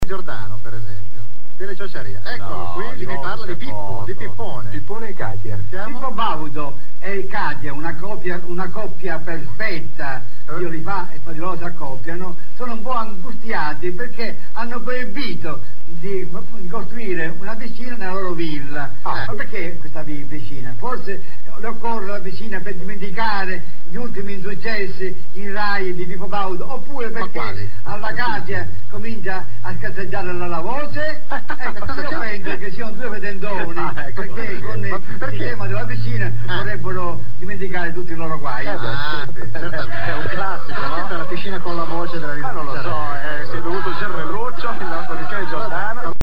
Ma ecco alcuni mp3 tratti da "Mai dire TV" della Gialappa's Band: